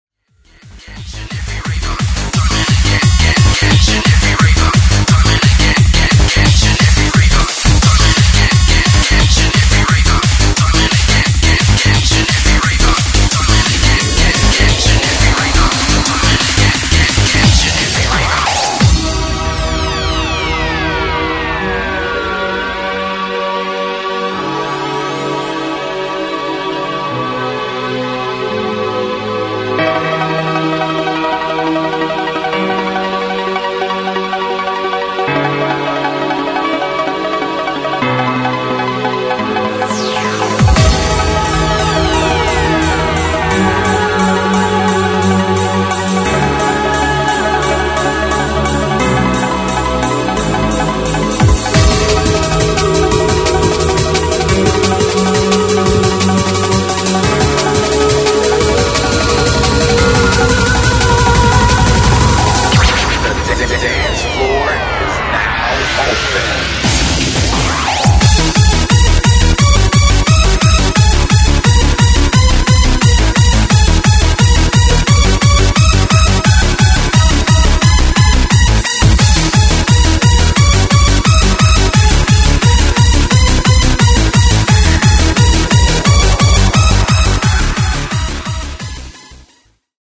Freeform/Hardcore/Happy Hardcore